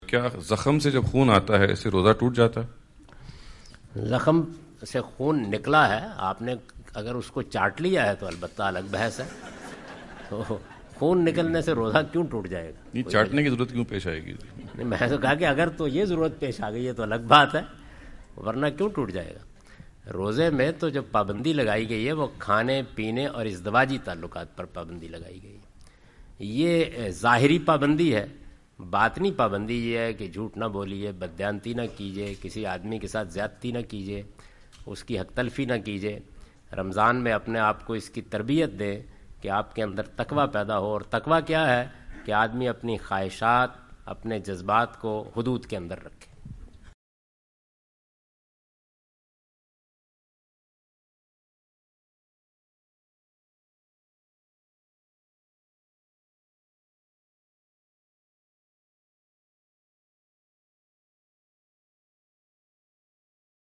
Javed Ahmad Ghamidi answer the question about "Effects of Bleeding on the Fast" during his US visit.
جاوید احمد غامدی اپنے دورہ امریکہ کے دوران ڈیلس۔ ٹیکساس میں "کیا روزے کے دوران خون بہنے سے روزہ ٹوٹ جاتا ہے؟" سے متعلق ایک سوال کا جواب دے رہے ہیں۔